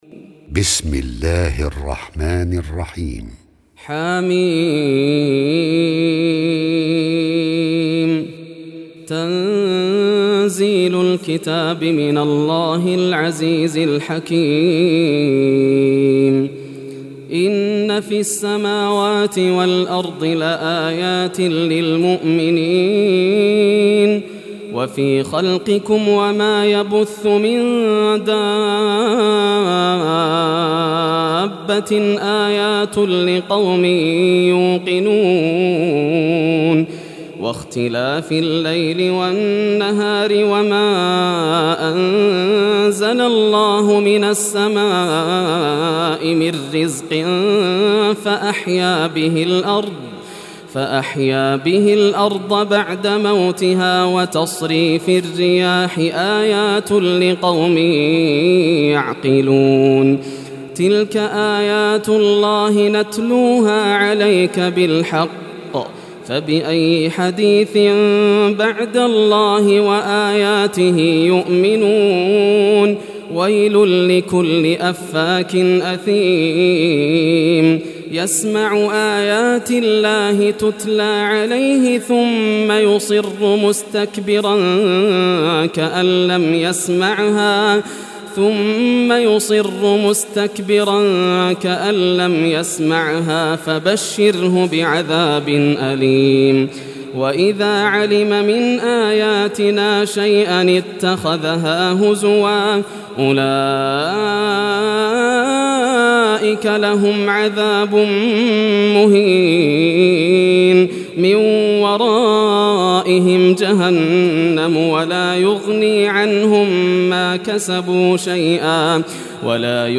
Surah Al-Jathiyah Recitation by Yasser Al Dosari
Surah Al-Jathiyah, listen or play online mp3 tilawat / recitation in Arabic in the beautiful voice of Sheikh Yasser al Dosari.